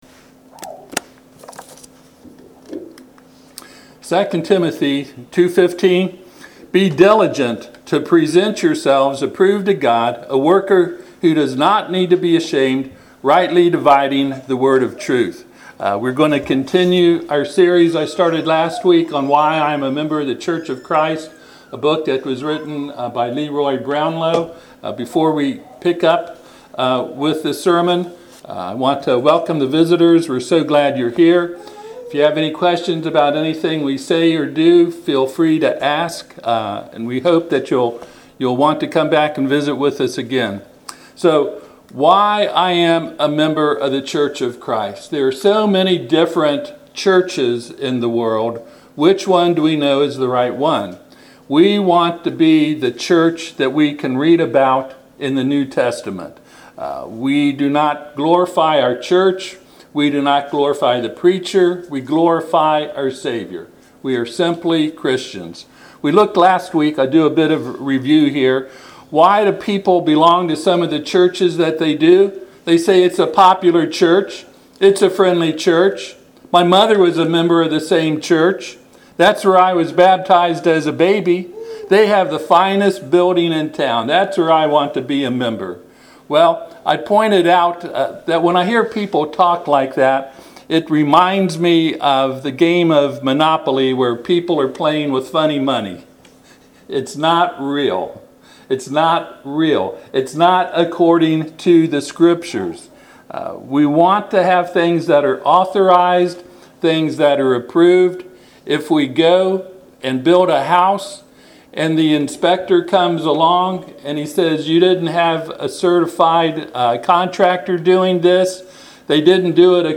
Passage: 2 Timothy 2:15 Service Type: Sunday PM